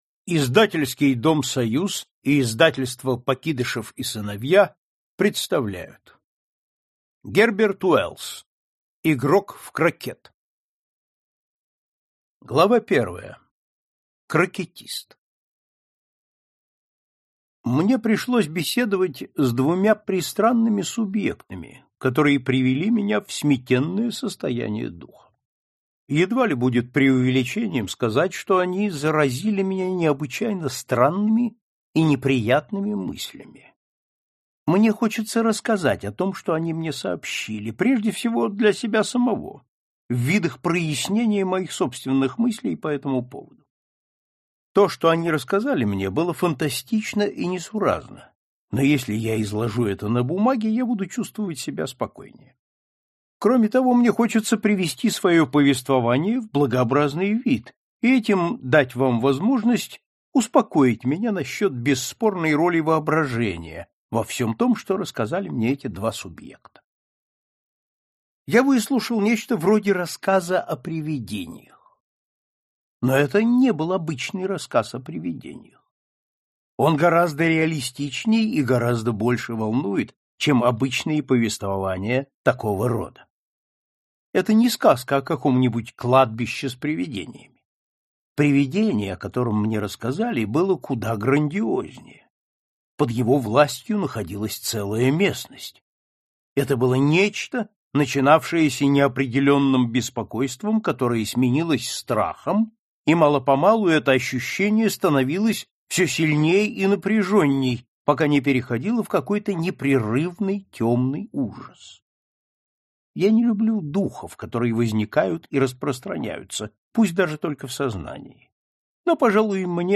Аудиокнига Игрок в крокет | Библиотека аудиокниг